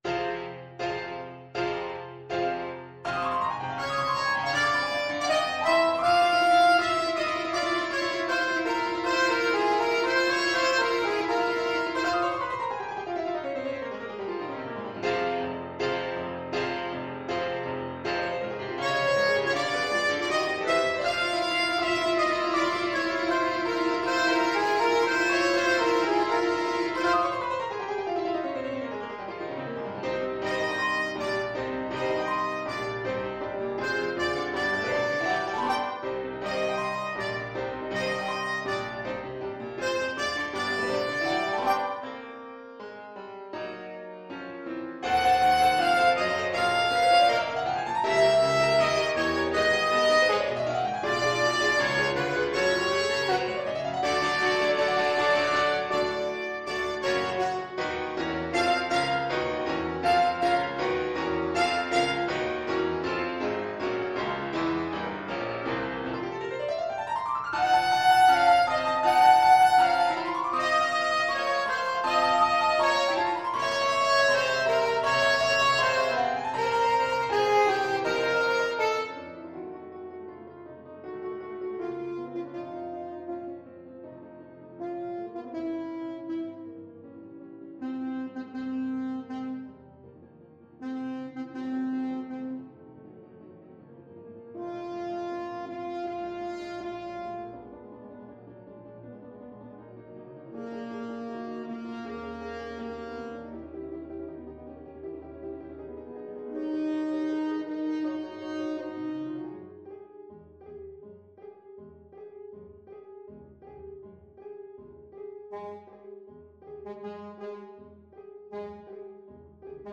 Classical Verdi, Giuseppe Dies Irae from Requiem Alto Saxophone version
Allegro agitato (=80) (View more music marked Allegro)
F minor (Sounding Pitch) D minor (Alto Saxophone in Eb) (View more F minor Music for Saxophone )
Classical (View more Classical Saxophone Music)